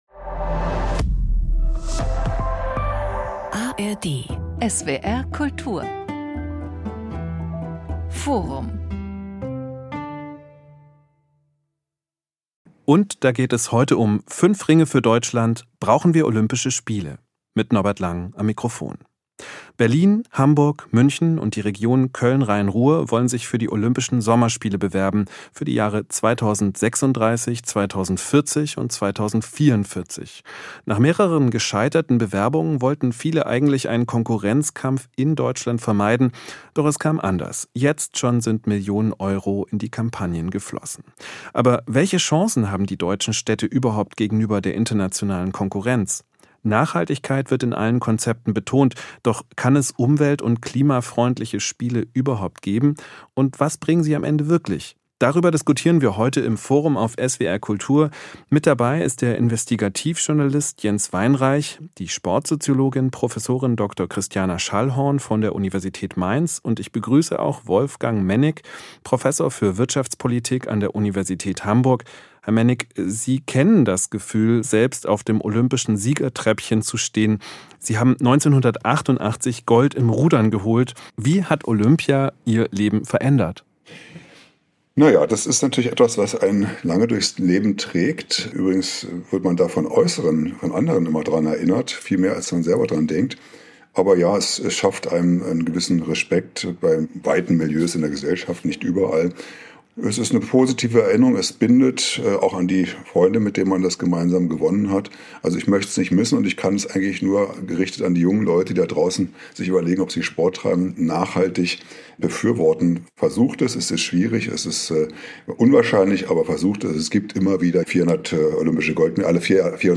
Sportsoziologin
Sportjournalist